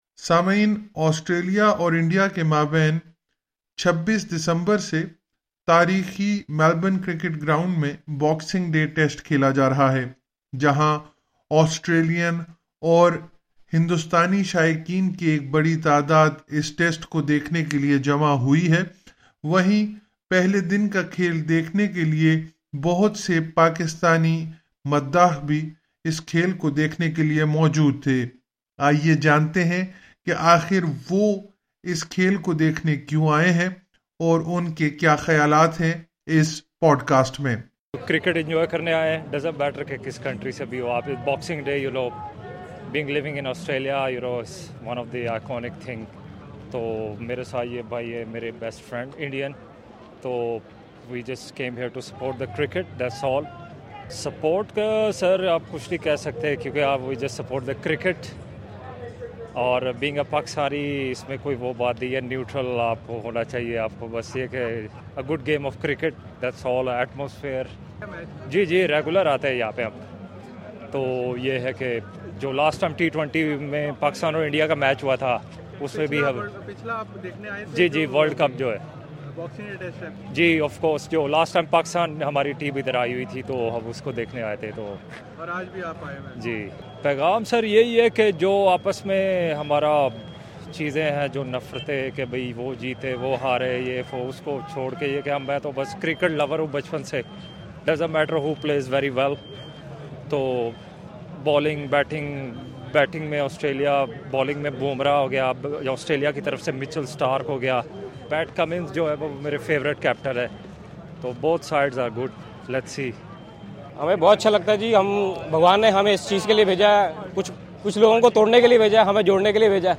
باکسنگ ڈے ٹیسٹ آسٹریلیا کی کرکٹ کی تاریخ میں ایک اہم مقام رکھتا ہے جہاں اسے دیکھنے کے لیے لوگ دور دراز علاقوں سے آتے ہیں وہیں وہ مداح بھی آتے ہیں جن کی ٹیم میچ میں شامل بھی نہیں ہوتی۔ اس پوڈکاسٹ میں ہم نے پاکستانی شائقین سے بات کی ہے جو یہ میچ دیکھنے آئے ہیں۔